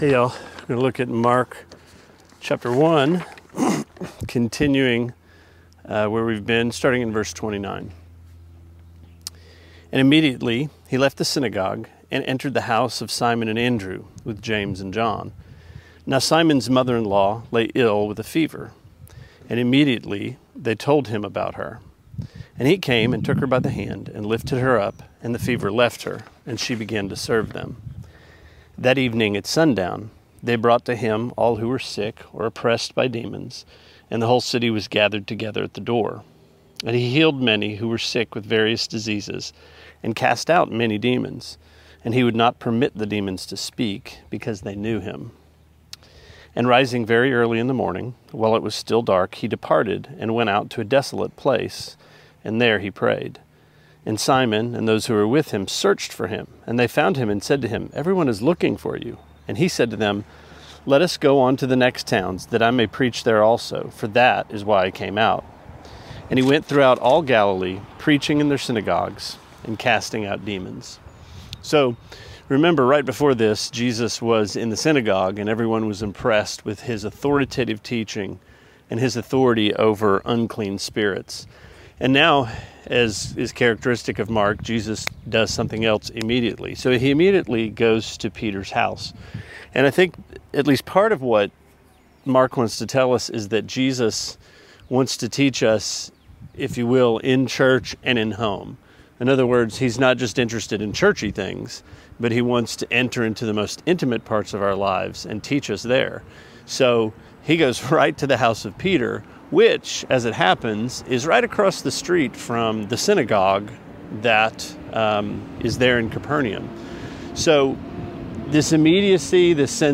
Sermonette 6/15: Mark 1:29-39: The Secret of Greatness